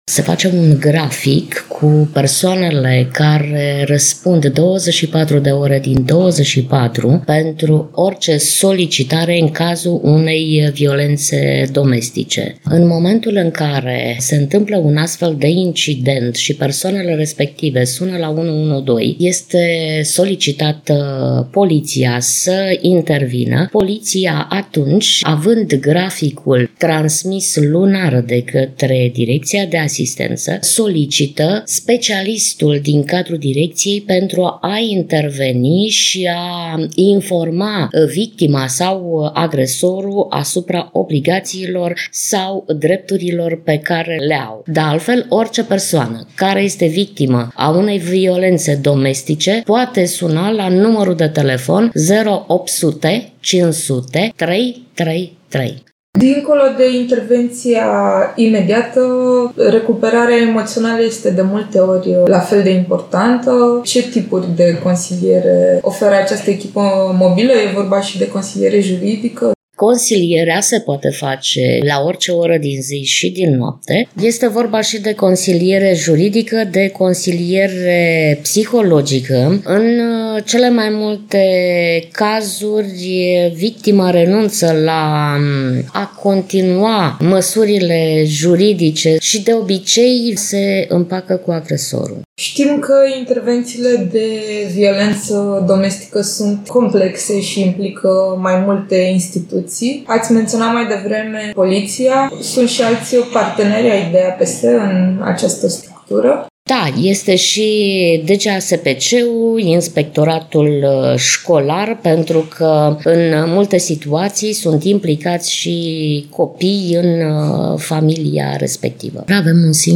Într-un interviu acordat Radio Constanța